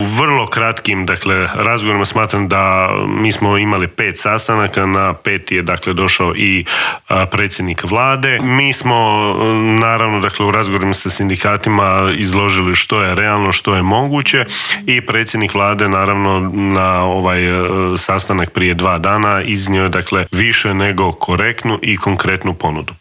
ZAGREB - Gostujući u Intervjuu tjedna Media servisa ministar rada, mirovinskoga sustava, obitelji i socijalne politike Marin Piletić otkrio je detalje pregovora sa sindikatima oko povišica, ali se osvrnuo i na najavu zabrane rada nedjeljom, kritikama na novi Zakon o radu, kao i o novostima koje stupaju na snagu 1. siječnja, a tiču se minimalne plaće i mirovina.